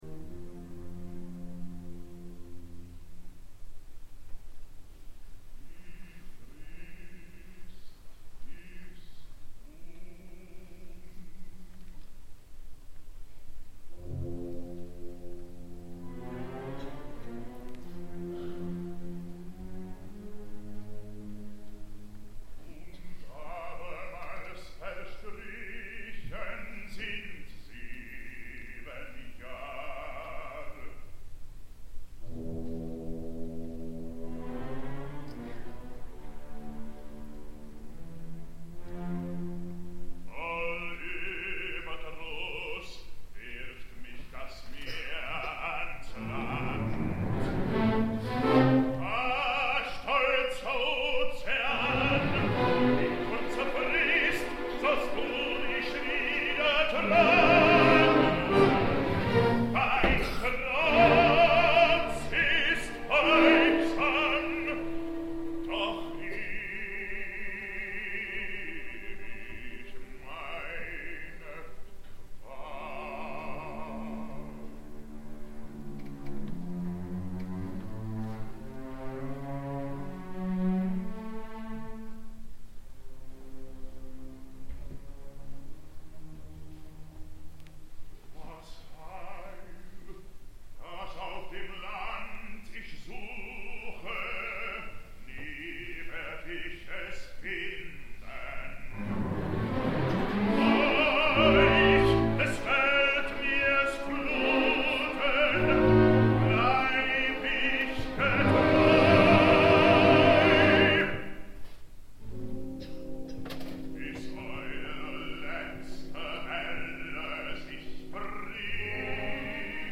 D’ençà que Bryn Terfel va debutar l’any 1990, tothom va voler veure el baix baríton que seria capaç de tornar a fer possible un Wotan, un Holandès, o un Amfortas que seguís l’estel deixat per Hotter o London.